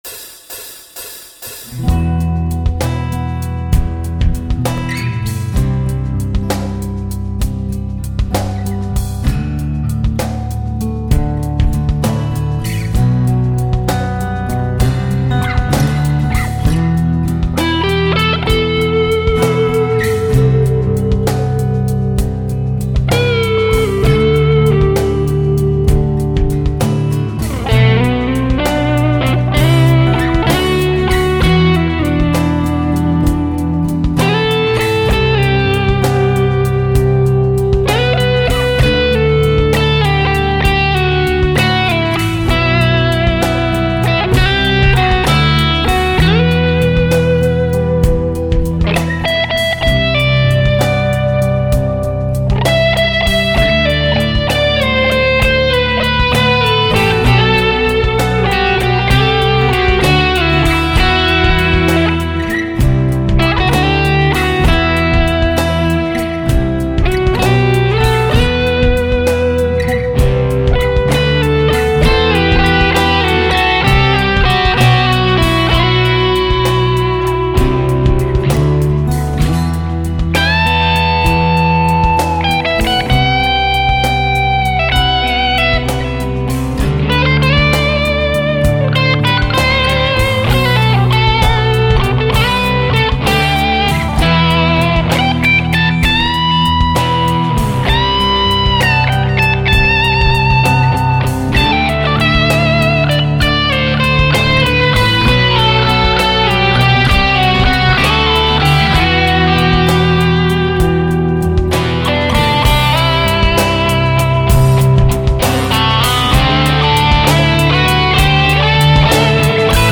ich habe natürlich auch was zum Backing eingespielt, es sind sogar zwei Versionen dabei rausgekommen.
Paws & Wings - PAWS (electric version) - mp3
Wenn wir mit der Band das schonmal (ein, zwei mal im Jahr) so locker zum Warmspielen dudeln, dann ist es eigentlich immer eher rockig, daher habe ich diese Version eingespielt.
Die Gitarrenspuren habe ich alle mit meiner "neuen" Orville Les Paul eingespielt